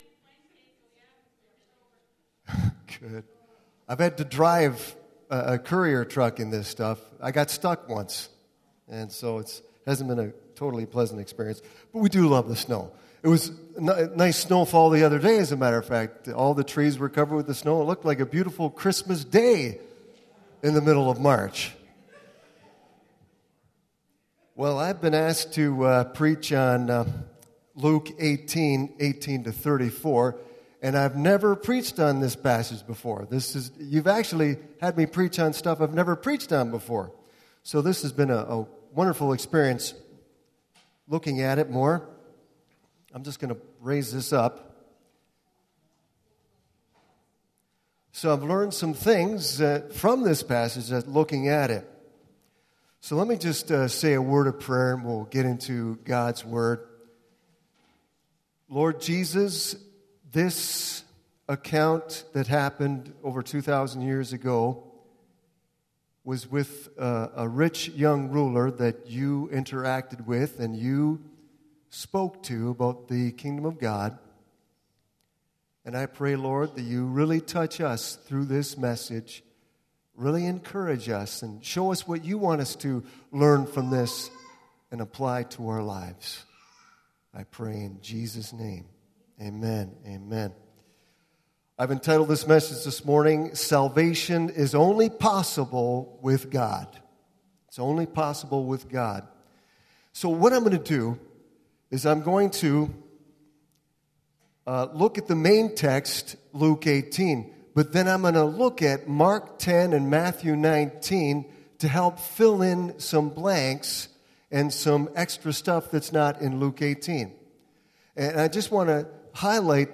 This sermon is based on Luke 18:18-34.